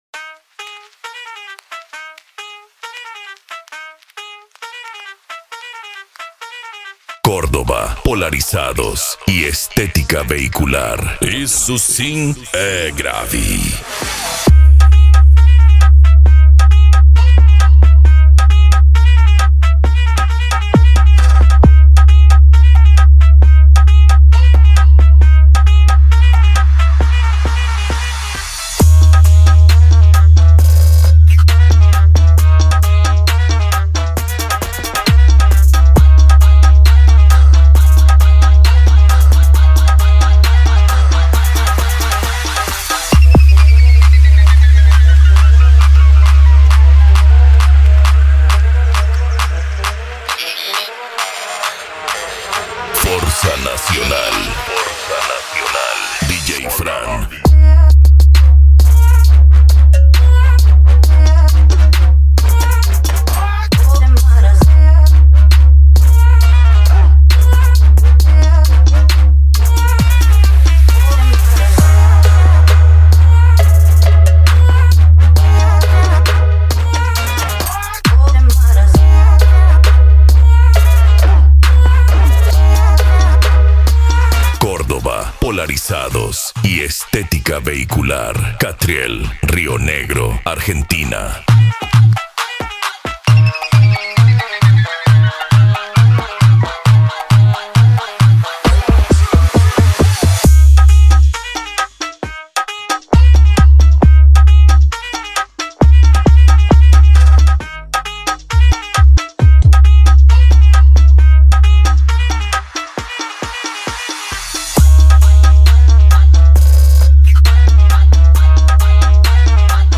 Bass
Electro House
Eletronica
Musica Electronica